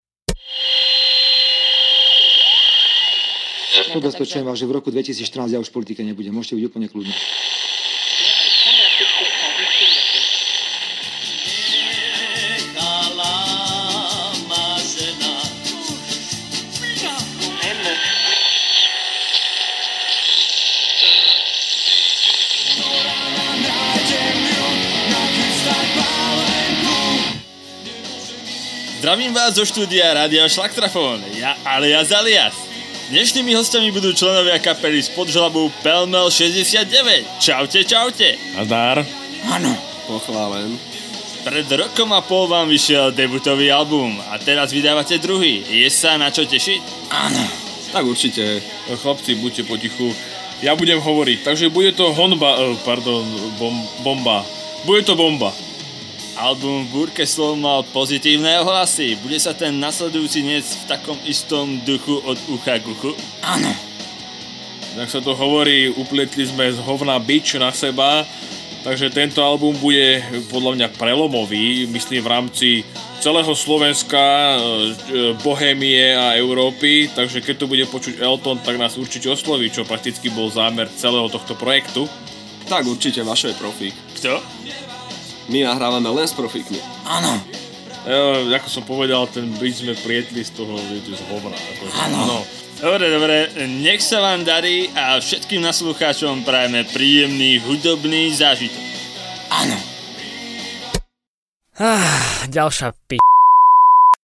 Žánr: Rock
gitara, spev
basgitara, spev
bicie, spev